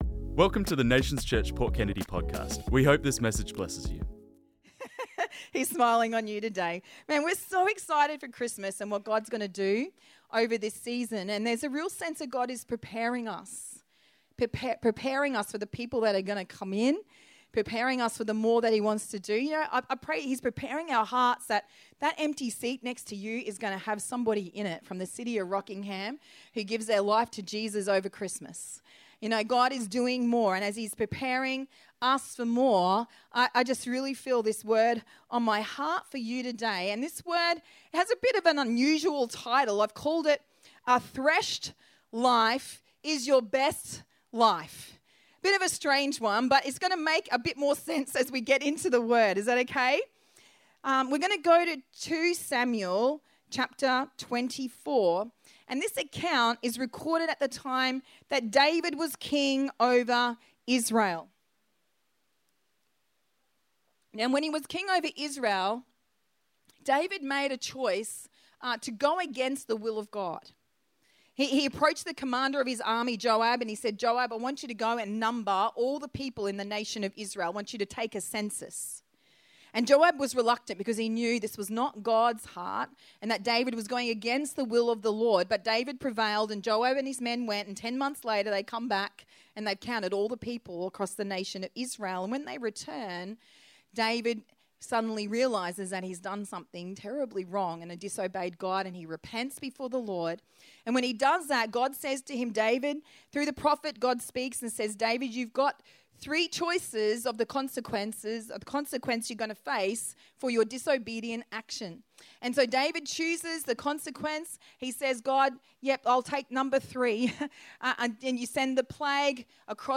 This message was preached on Sunday the 19th October 2025